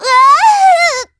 Ripine-Vox_Damage_kr_05.wav